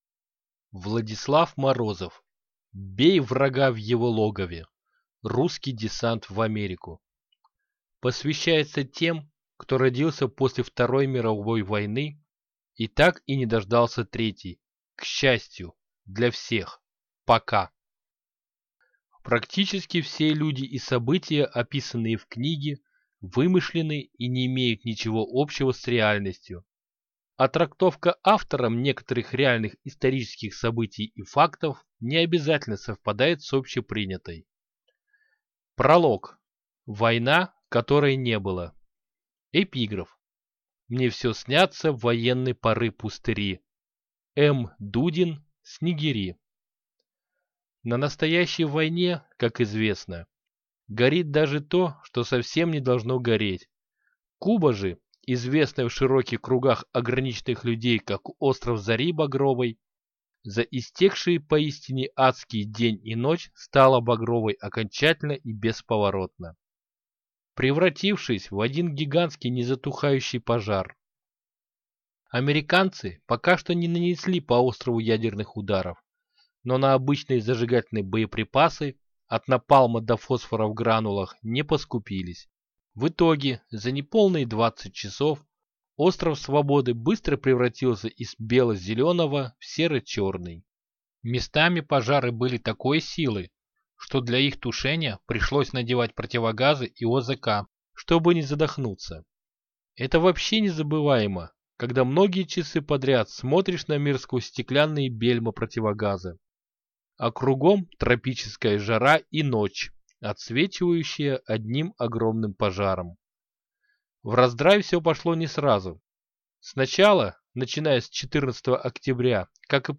Аудиокнига Бей врага в его логове! Русский десант в Америку | Библиотека аудиокниг